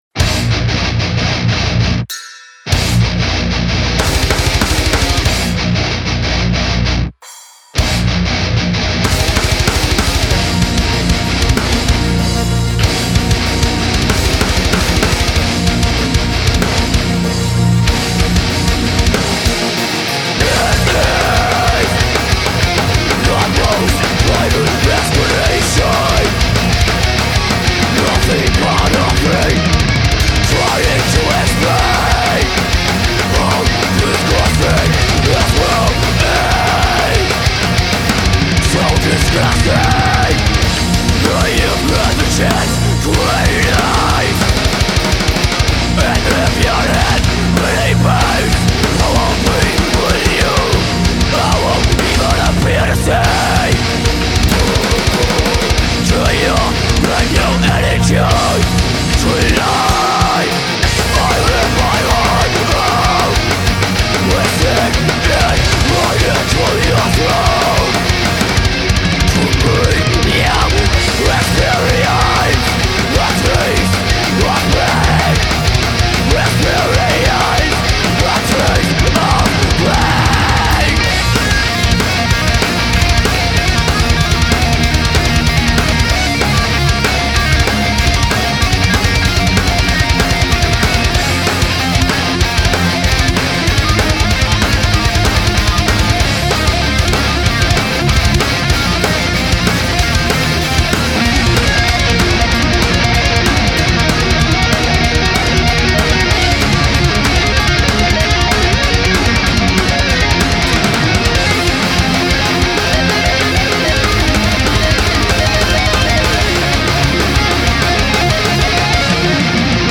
Bock Auf nen fies-brutalen Müller Mustang Clip?
na denn mal los: Müller Mustang in Bb